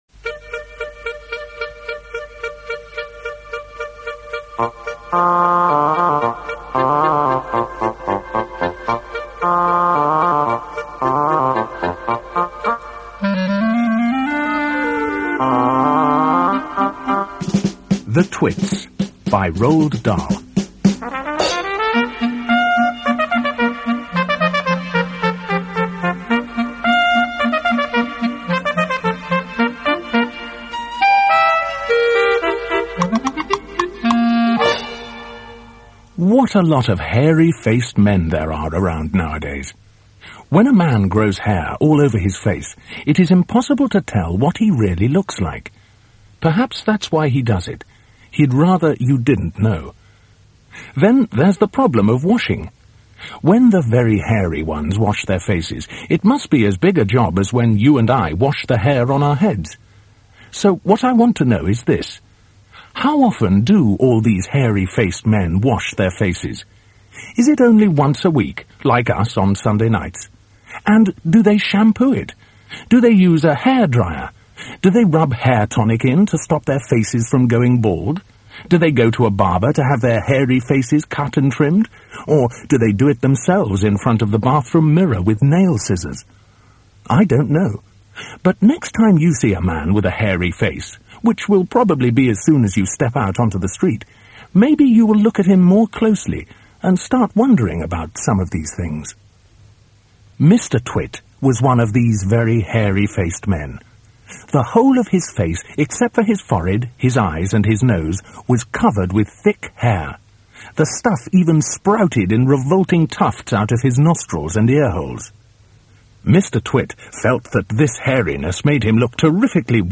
دانلود رایگان کتاب انگلیسی کله‌پوک‌ها اثر رولد دال بدون سانسور- The Twits audiobook & e-book by Roald Dahl